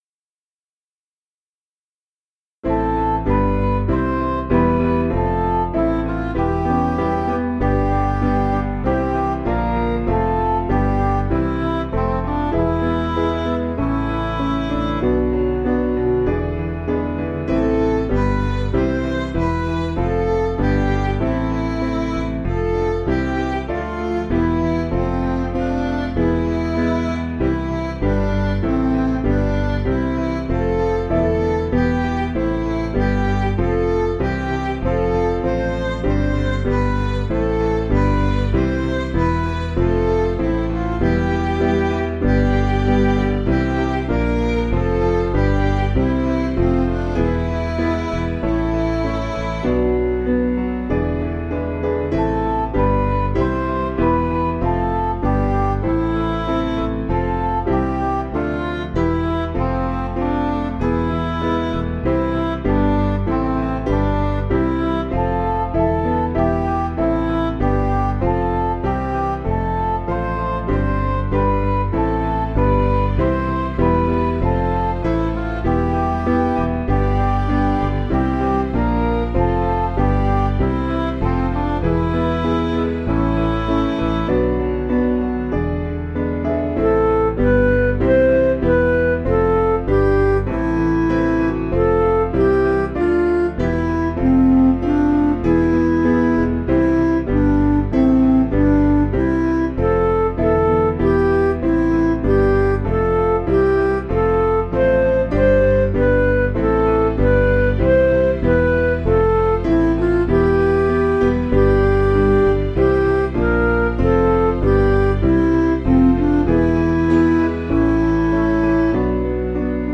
Piano & Instrumental
(CM)   5/Am